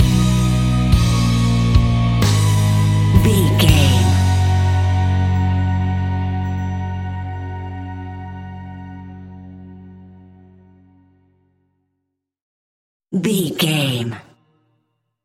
Ionian/Major
indie pop
fun
energetic
uplifting
instrumentals
upbeat
groovy
guitars
bass
drums
piano
organ